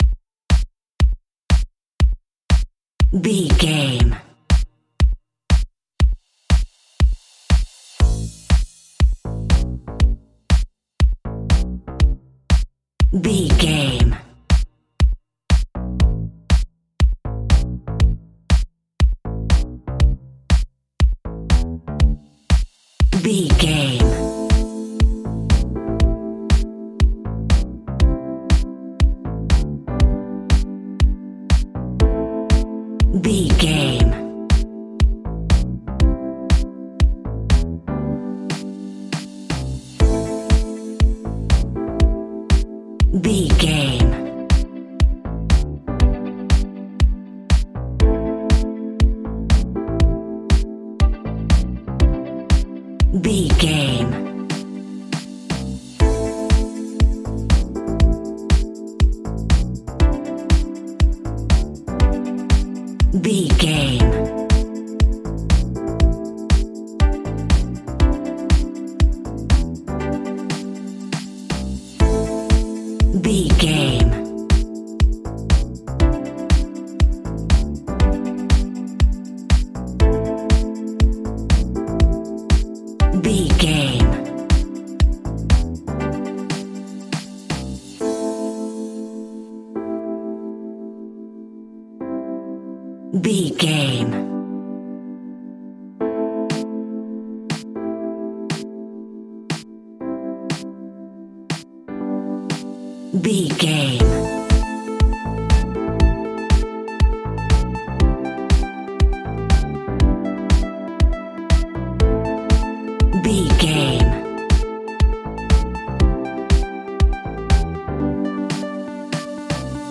Aeolian/Minor
groovy
uplifting
driving
energetic
electric piano
bass guitar
synthesiser
drums
funky house
deep house
nu disco
upbeat
funky guitar
wah clavinet
synth bass